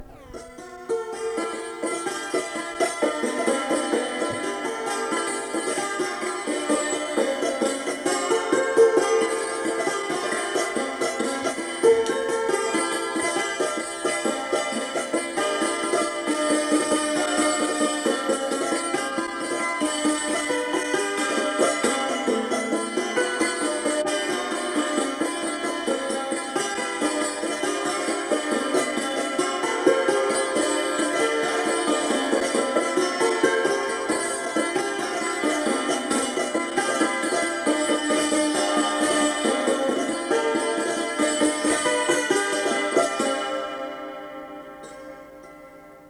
šokis
Adutiškis
instrumentinis
cimbolai